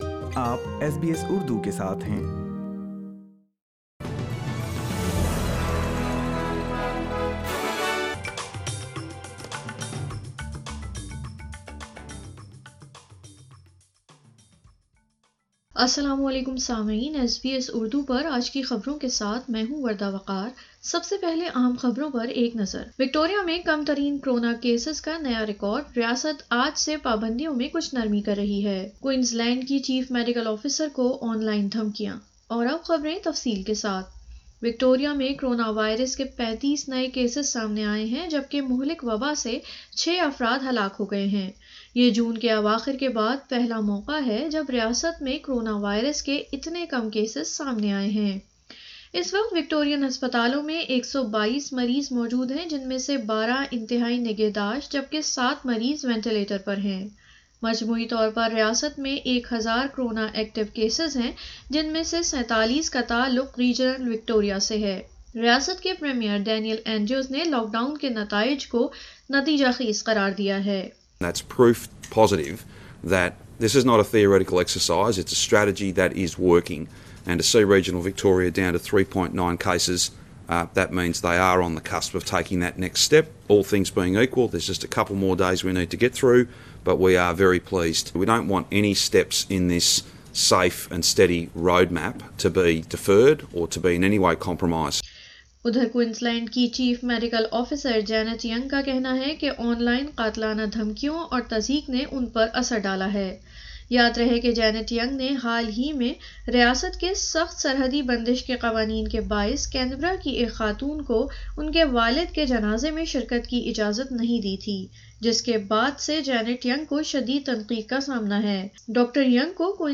اردو خبریں 14 ستمبر 2020